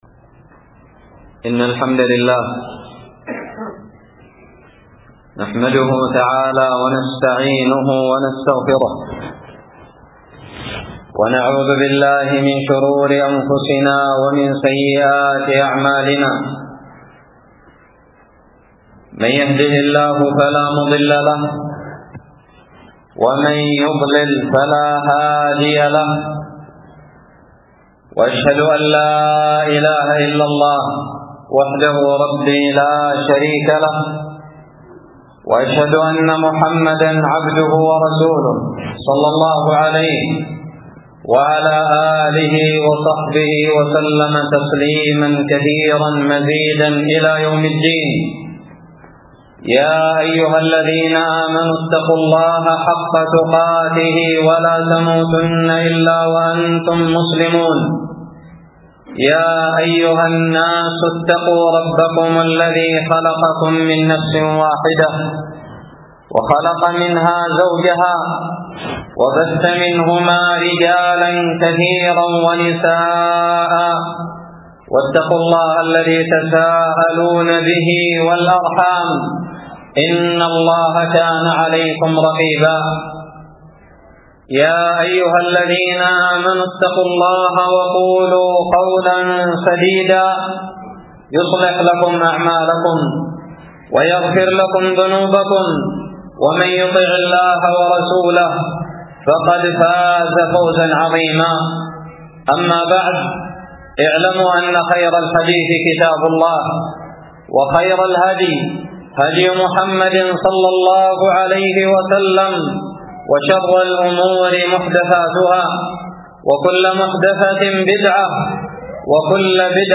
خطب الجمعة
ألقيت بدار الحديث السلفية للعلوم الشرعية بالضالع في 11 صفر 1438هــ